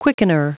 Prononciation du mot quickener en anglais (fichier audio)
Prononciation du mot : quickener